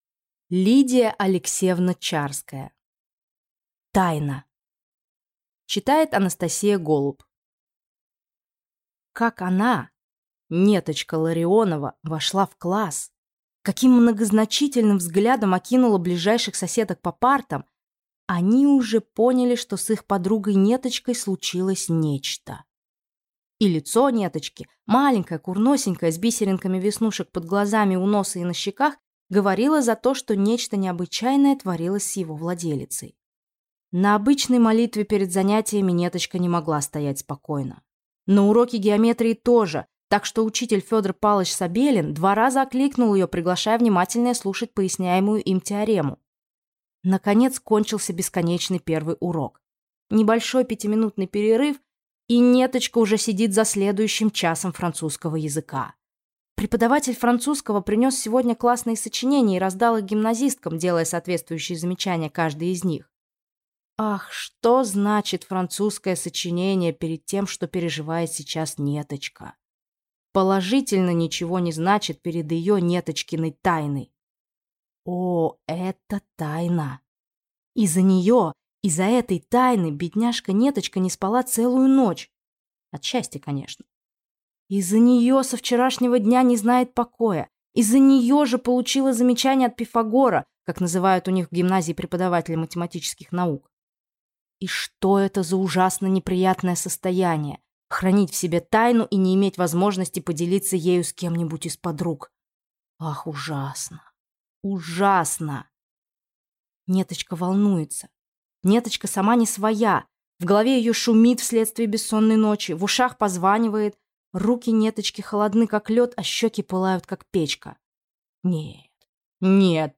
Аудиокнига Тайна | Библиотека аудиокниг